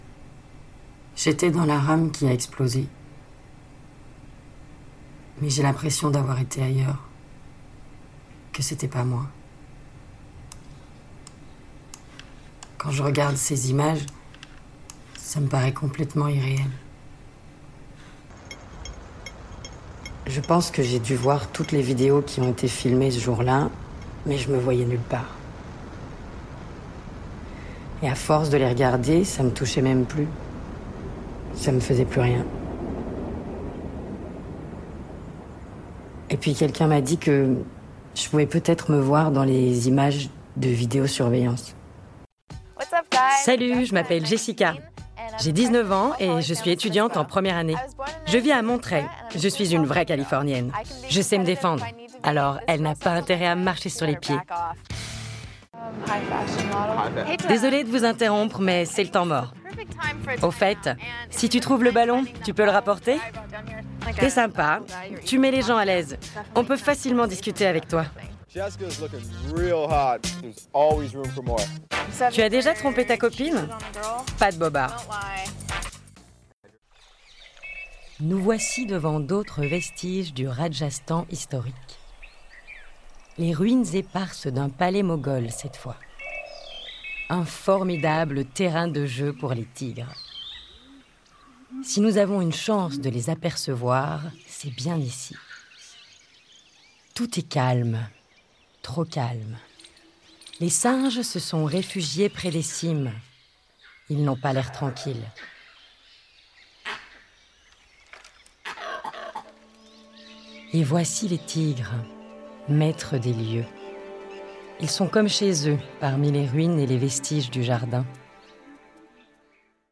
Démo voix
Voix - Mezzo-soprano